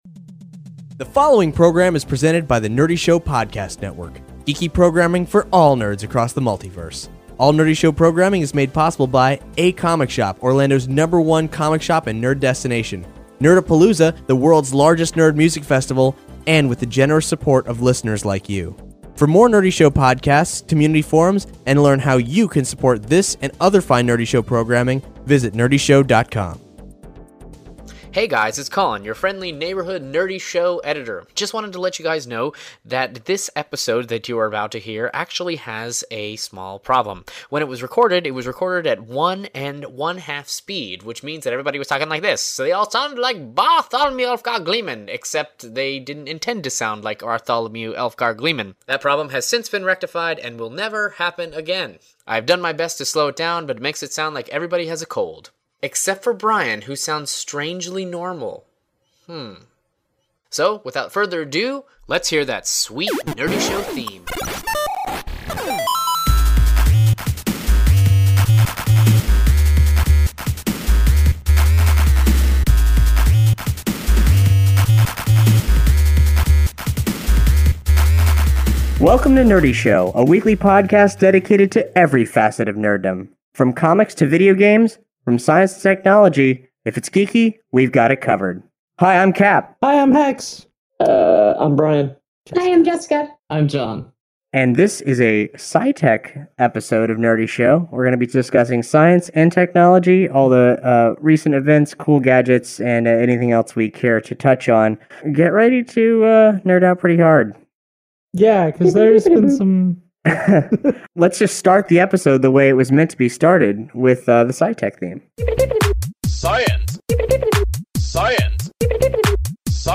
THIS EPISODE PRESENTED TO YOU IN GLORIOUS SPEED-WARP!
Some kinda temporal mess-up made our files all wonky! Still listenable, we just sound weird.